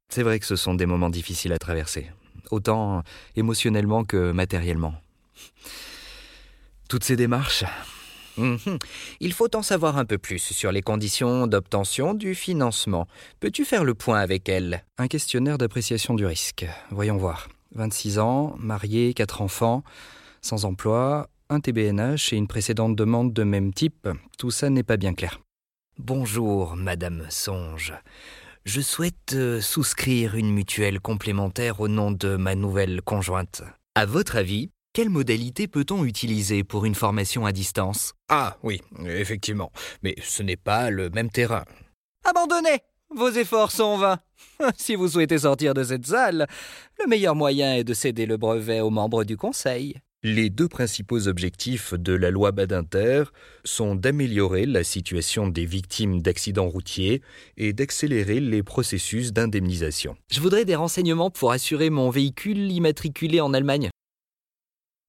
Never any Artificial Voices used, unlike other sites.
Male
French (European), French (Parisienne)
Yng Adult (18-29), Adult (30-50)
French E-learning Character